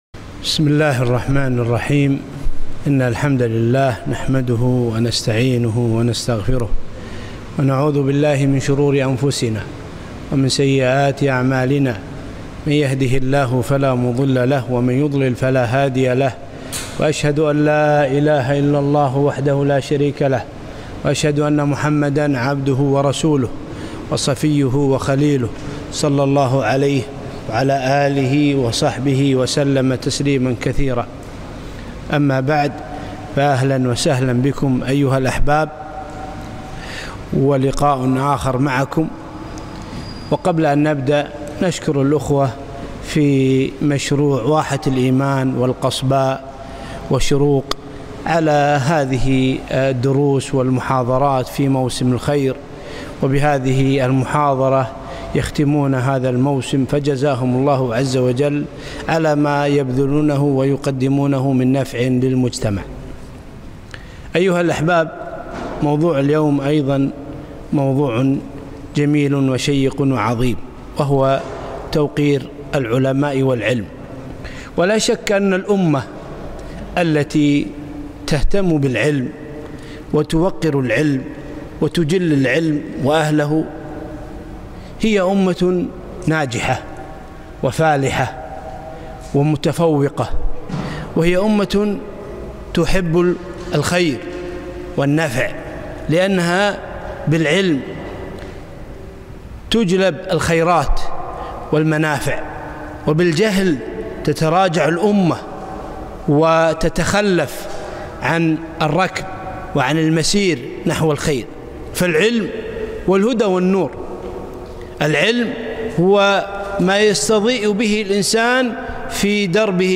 محاضرة - حقوق العلماء